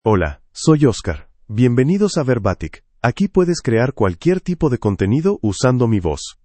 MaleSpanish (United States)
Oscar is a male AI voice for Spanish (United States).
Voice sample
Listen to Oscar's male Spanish voice.
Oscar delivers clear pronunciation with authentic United States Spanish intonation, making your content sound professionally produced.